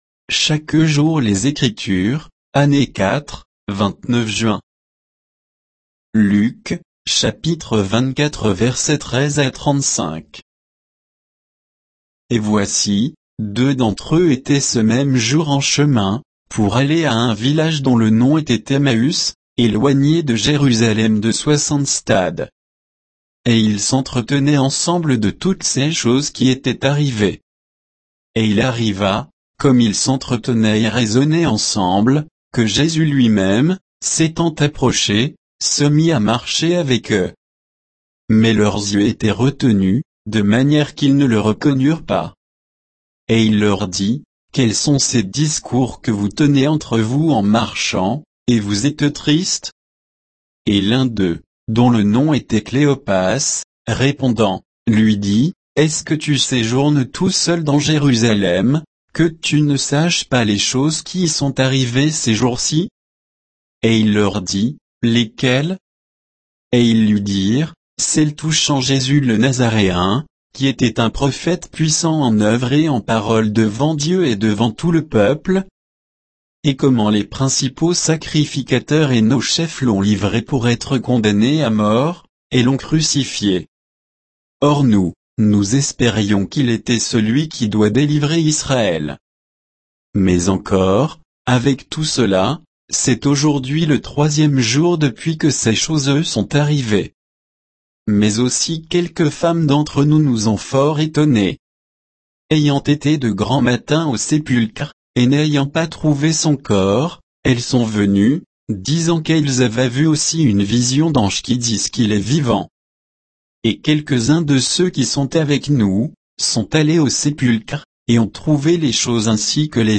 Méditation quoditienne de Chaque jour les Écritures sur Luc 24, 13 à 35